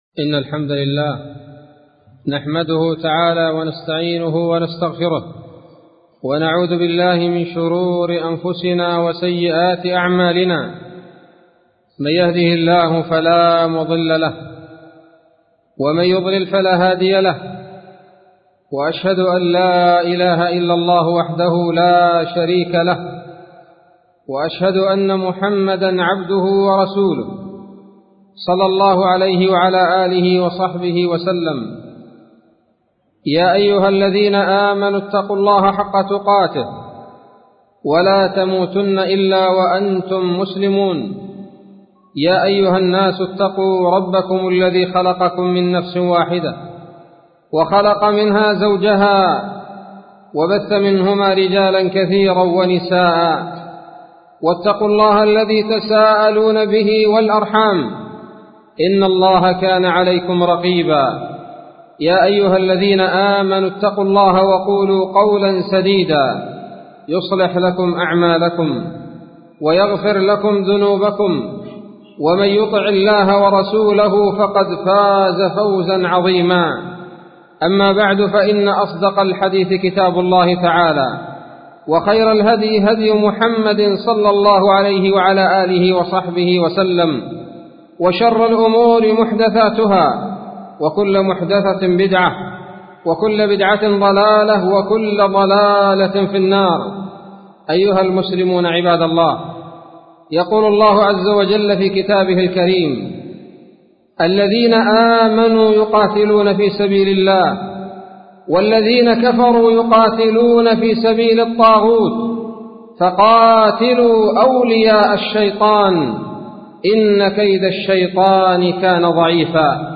خطبة بعنوان : ((الذين آمنوا يقاتلون في سبيل الله....الآية))